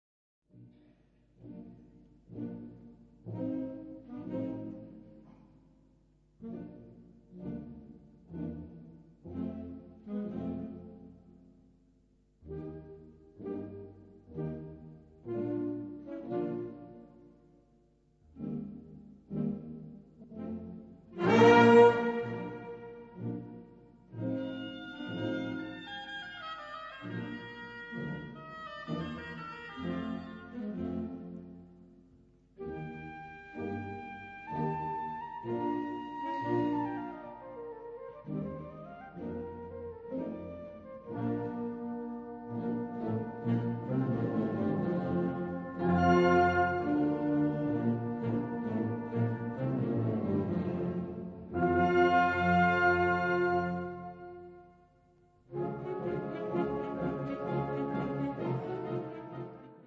Gattung: Ouvertüre
D-E Besetzung: Blasorchester PDF